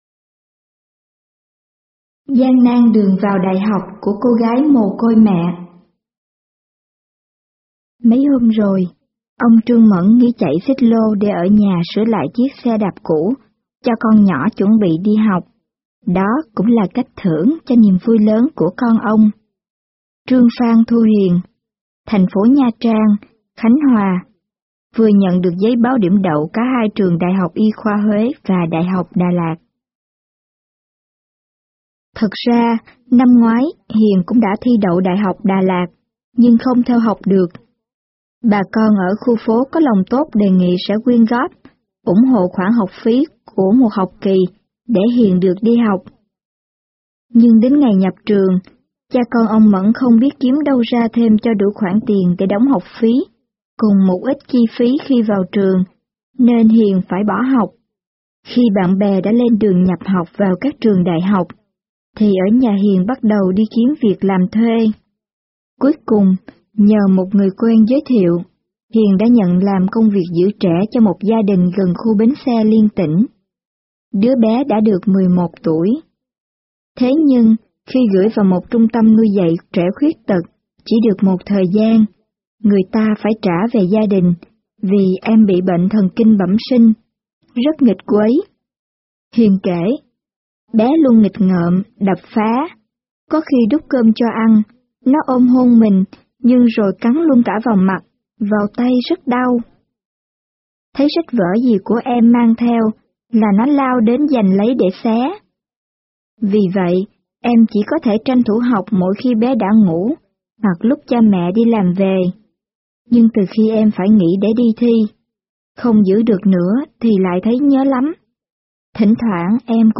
Sách nói | Gian nan đường vào đại học